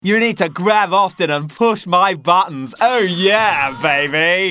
• Soundtrack Ringtones